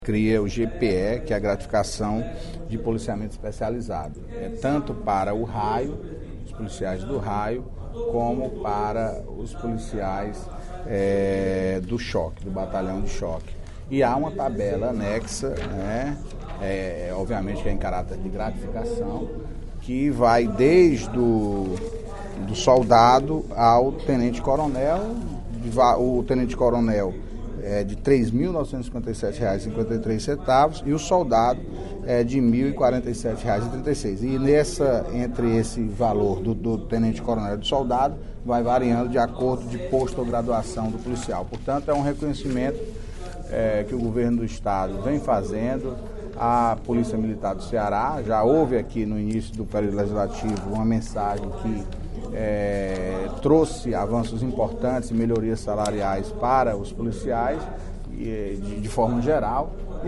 O líder do Governo na Assembleia Legislativa, deputado Antonio Carlos (PT), informou, nesta terça-feira (20/03) em plenário, que o Governo do Estado encaminhou a Casa, mensagem que cria a Gratificação de Policiamento Especializado (GPE).